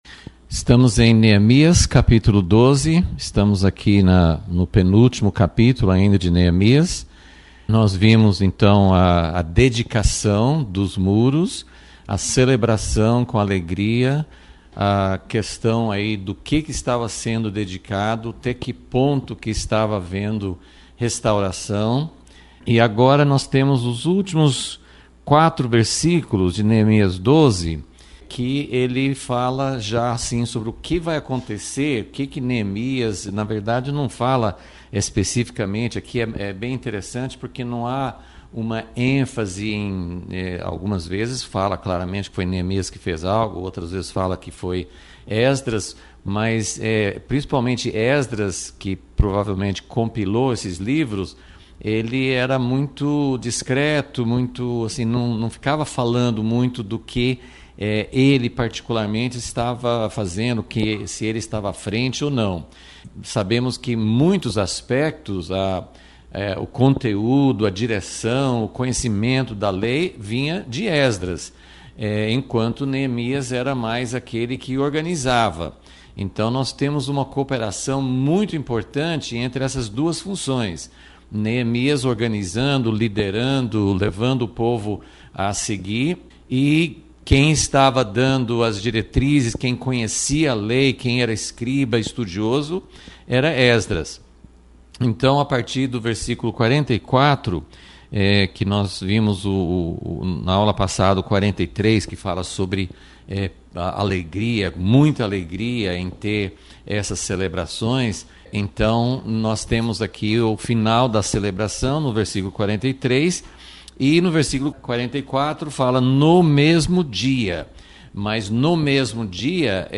Aula 32 – Vol.36 – E depois da celebração?